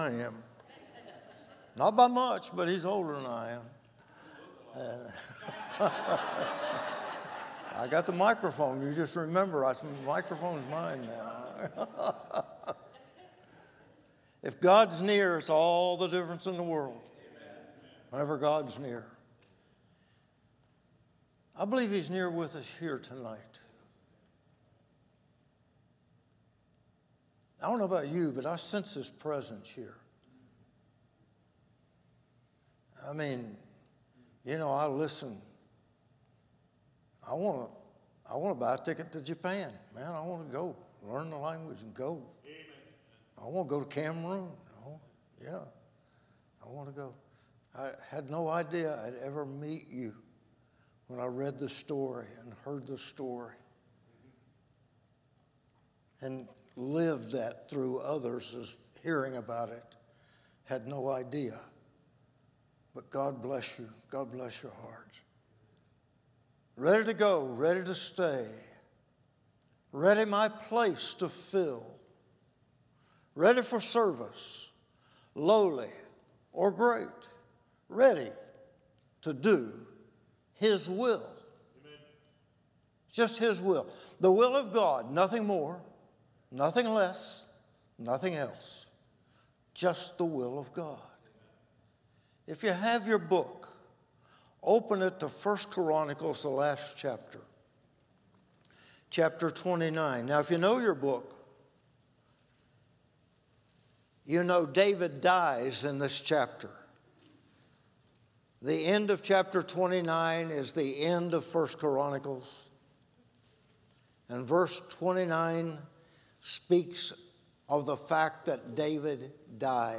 October 2022 Missions Conference Conference Scripture: 1 Chronicles 29: 1-5 Download: Audio